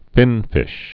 (fĭnfĭsh)